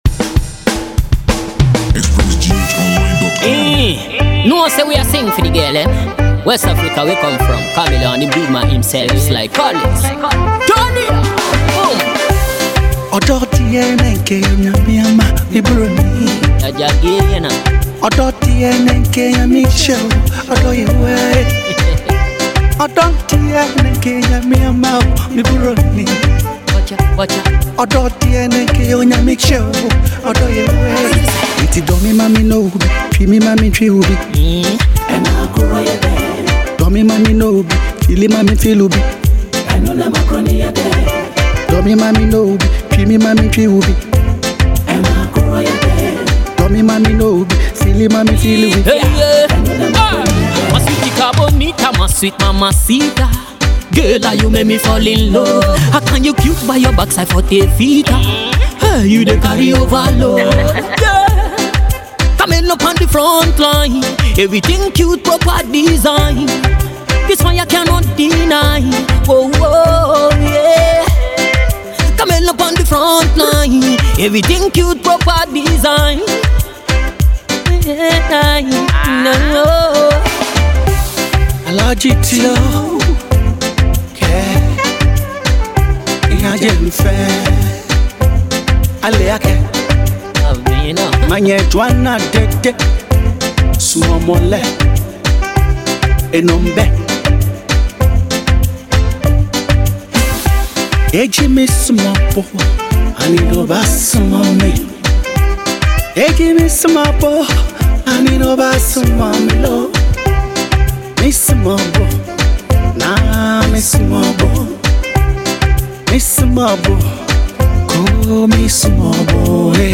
Ghanaian Dancehall
a new long song by Veteran Ghanaian Highlife Singer
young rapper